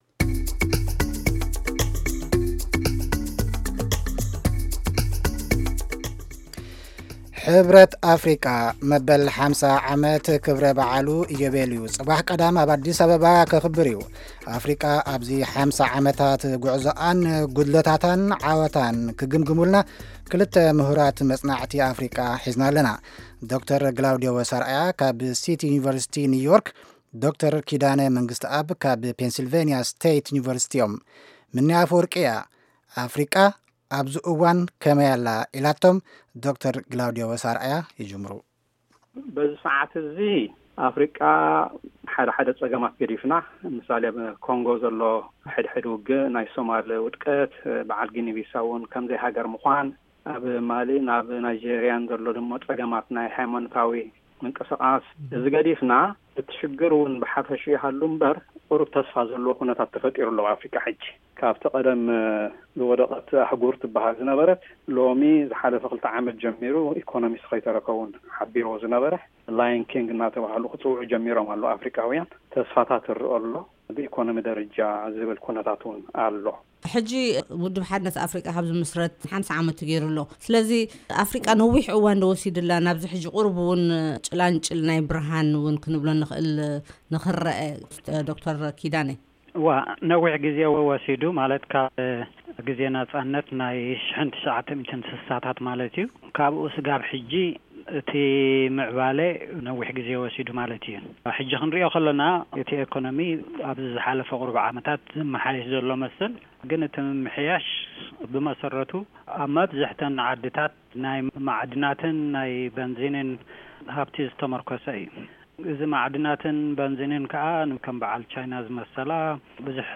ኣብ‘ዚ ኣህጉራዊ ኣኼባ ተመስሪቱ ዝተዳለወ ዕላል ምስ ክልተ ሙሁራት ታሪኽ ኣፍሪቃ ዘካየድናዮ መደብ ኣሎ።